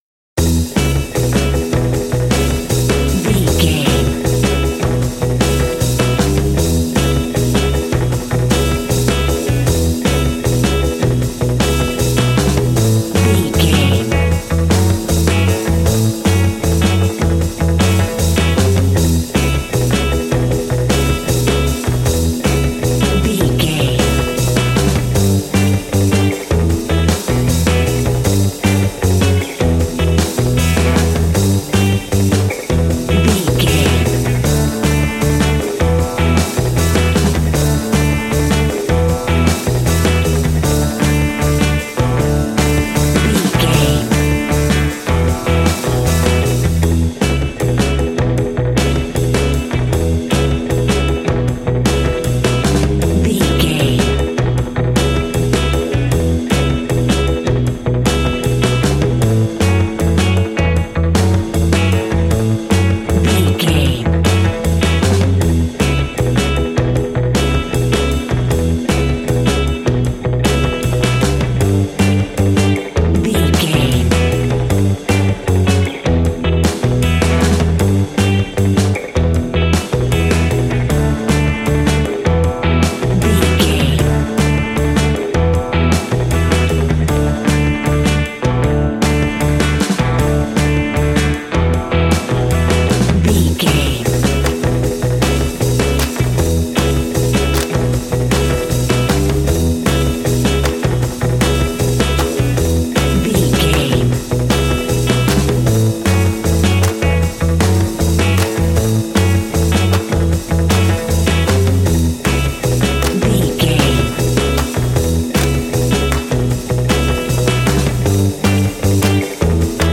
Ionian/Major
cool
uplifting
bass guitar
electric guitar
drums
cheerful/happy